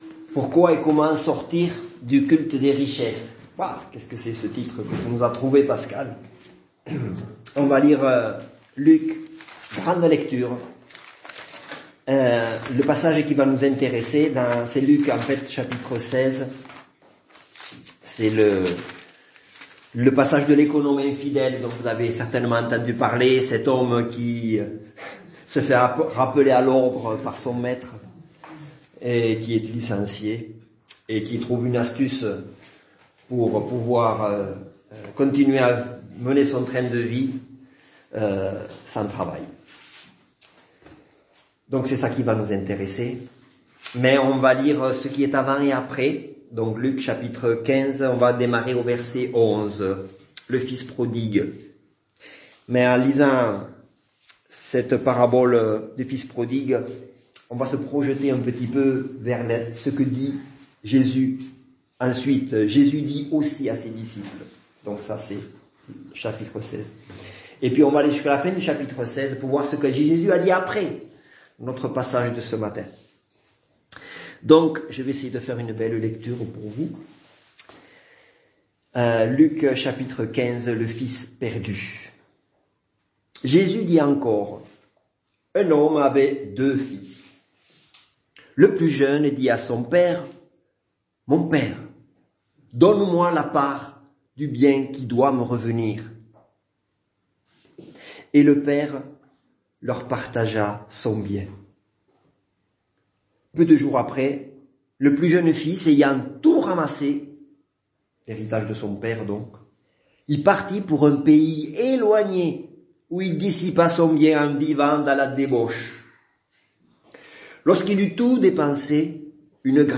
Culte du dimanche 31 juillet 2022 - EPEF
Prédication Pourquoi et comment sortir du culte des richesses.